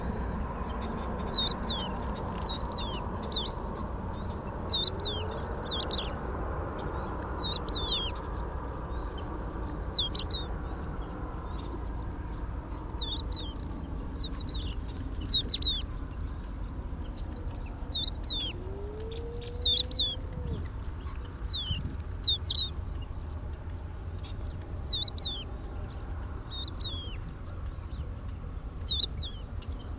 Cochevis huppé - Mes zoazos
cochevis-huppe.mp3